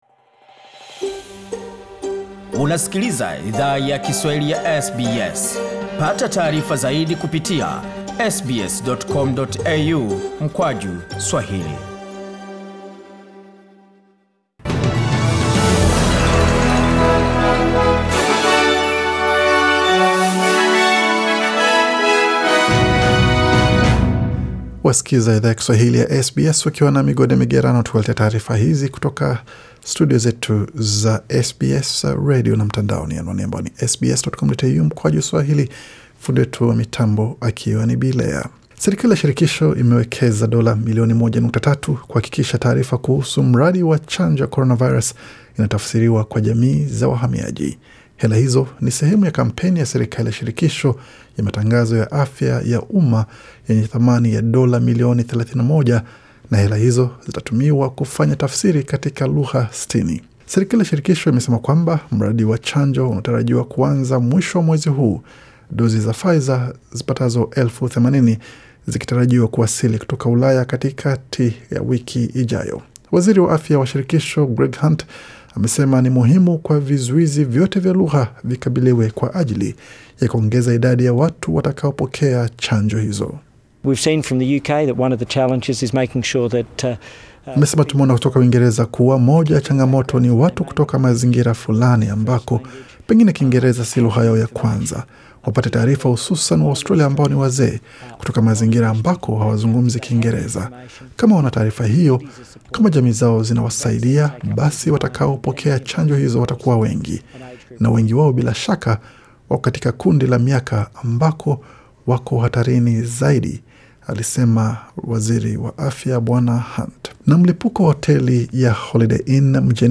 Taarifa ya habari 14 Februari 2021